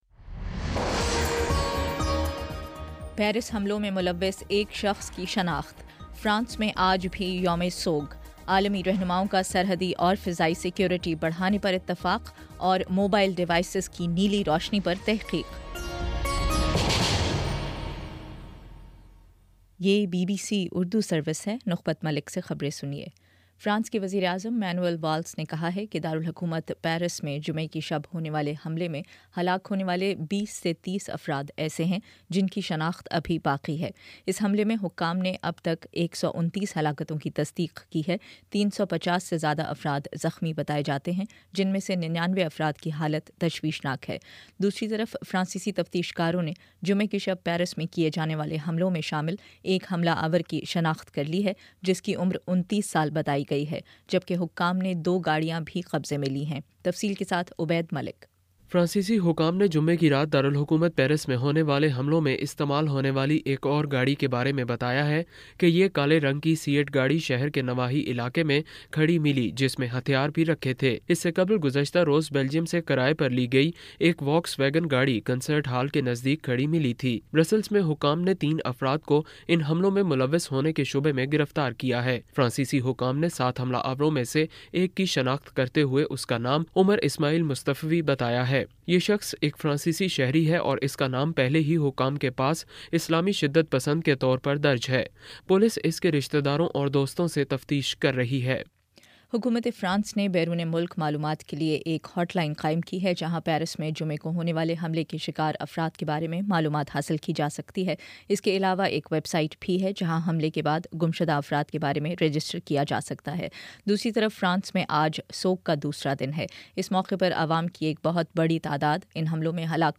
نومبر 15 : شام چھ بجے کا نیوز بُلیٹن